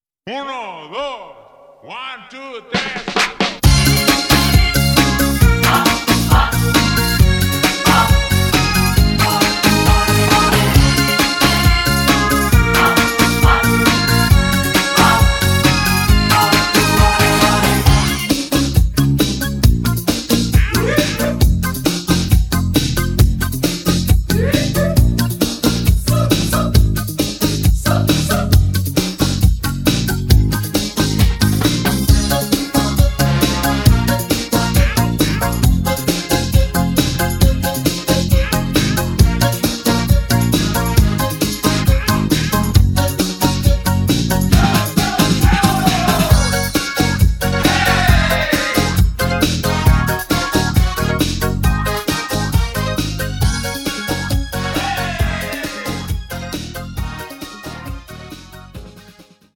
음정 -1키 3:13
장르 가요 구분 Voice MR